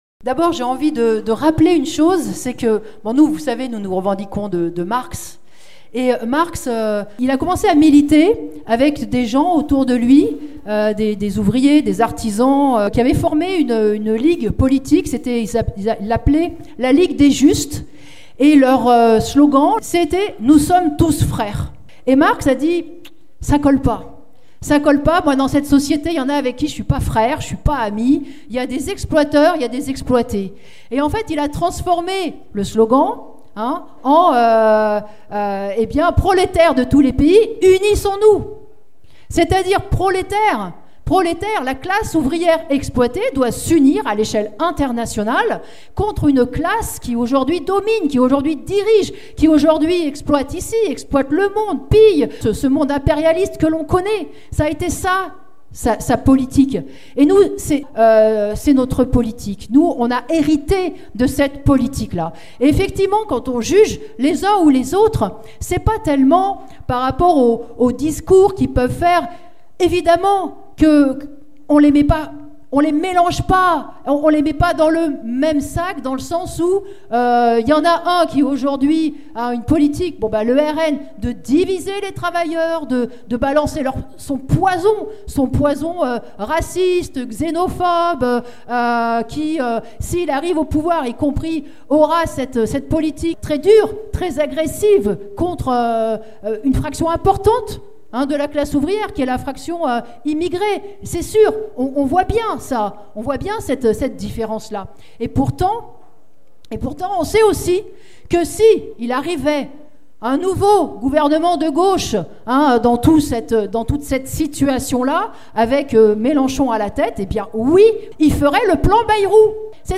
Nathalie Arthaud débat à la fête lyonnaise de LO : De gauche, de droite ou d'extrême droite, tout gouvernement sera au service de la bourgeoisie